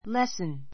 lesson 小 A1 lésn れ ス ン 名詞 ❶ （学校の） 授業 , 勉強; 稽古 けいこ , レッスン an English lesson an English lesson 英語の授業 a piano [dancing] lesson a piano [dancing] lesson ピアノ[ダンス]のレッスン Our first lesson today is English.